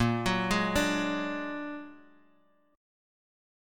Bb+7 Chord
Listen to Bb+7 strummed